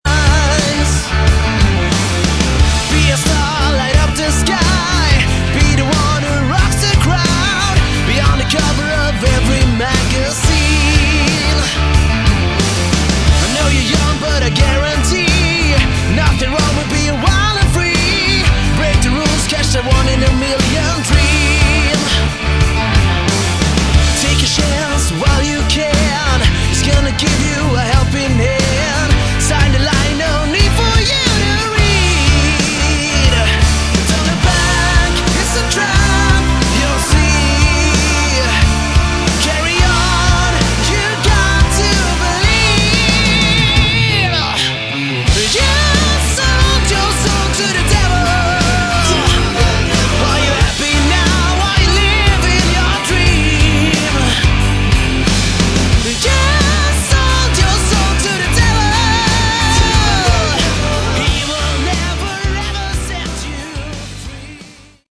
Category: AOR / Melodic Rock
lead vocals
guitar, background vocals
bass, background vocals
drums, background vocals